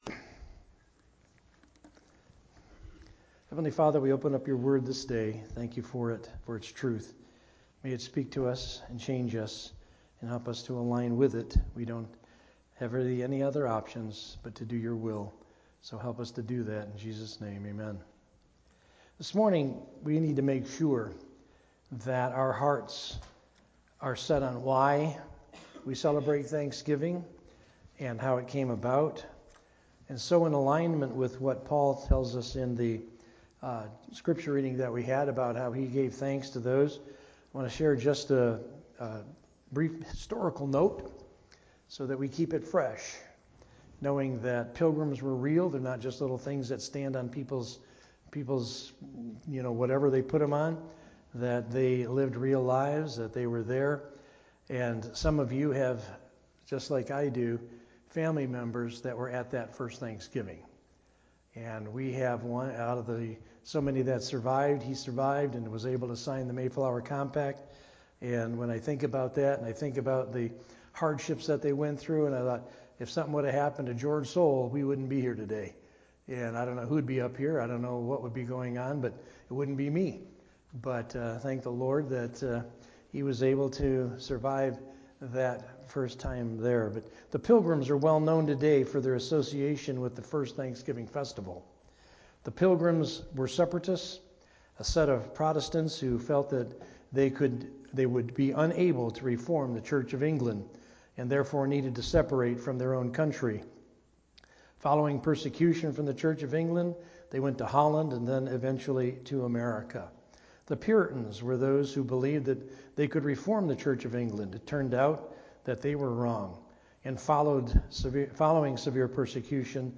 A message from the series "Sunday Morning - 11:00."